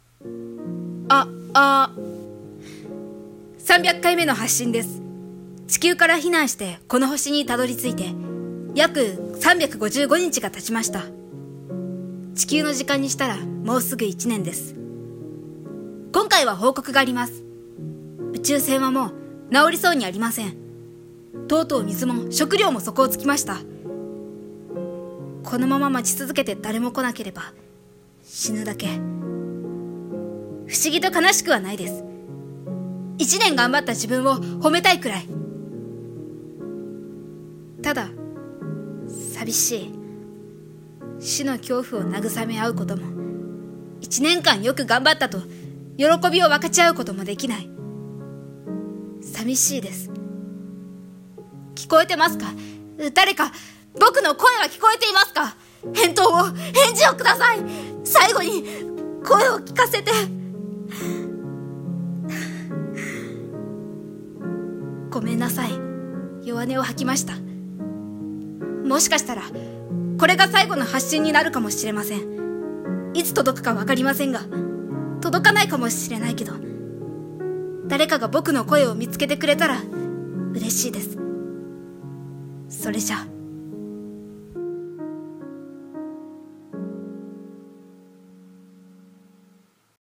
声劇「星の孤独